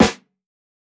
SNARE_GOLDEN.wav